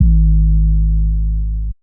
Metro 808s [Sub].wav